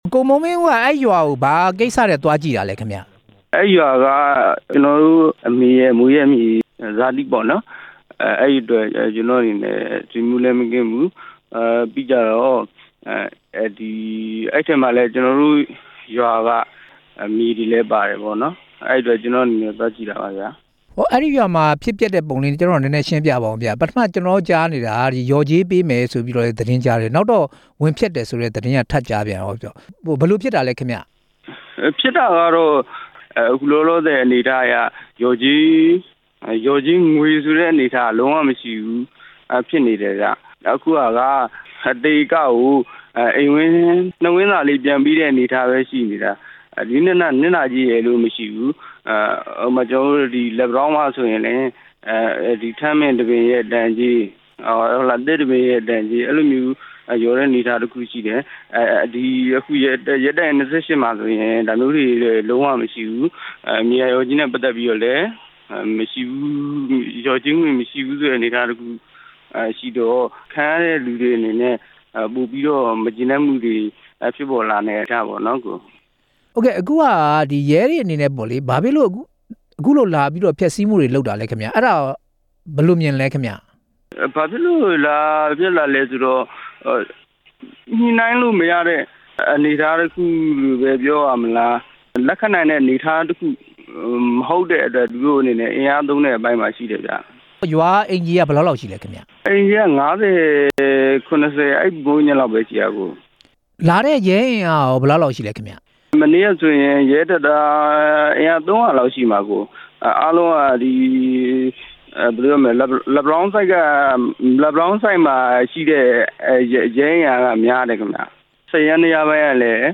မျက်မြင်သက်သေတစ်ဦးနဲ့ မေးမြန်းချက်